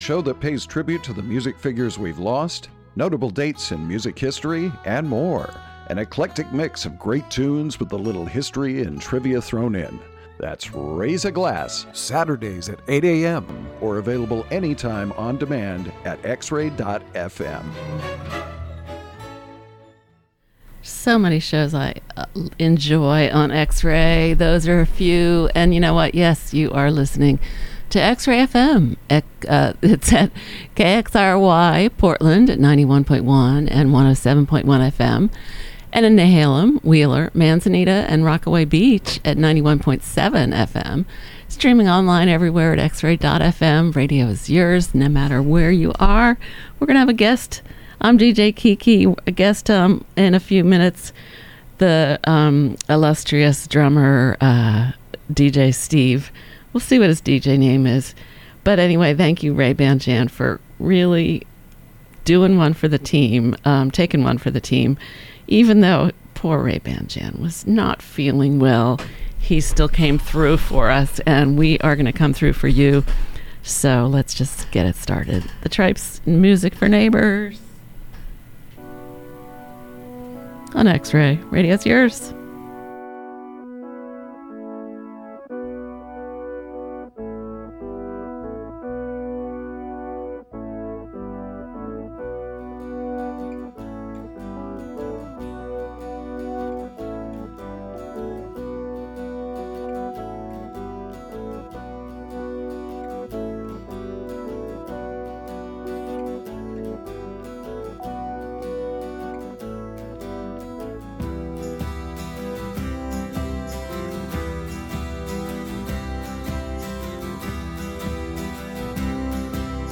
Special emphasis on the News (Zealand, Jersey and York).